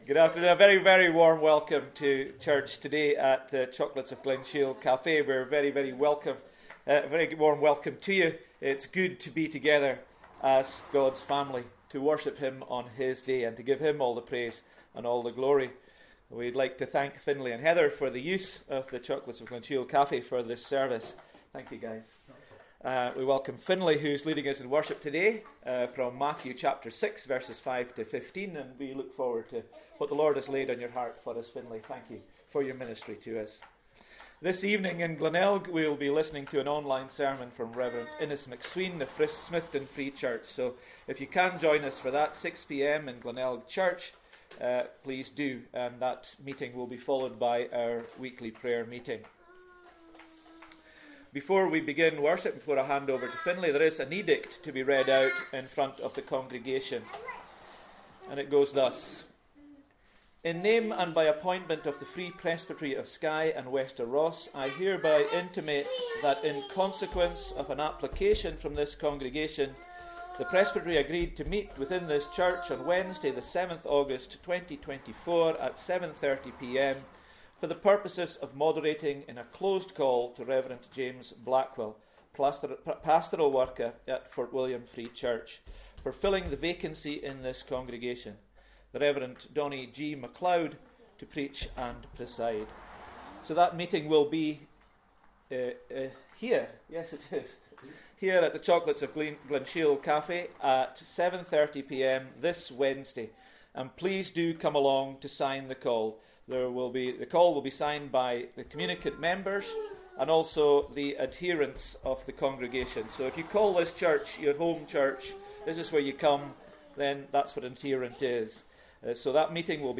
Sunday Service 4th August 2024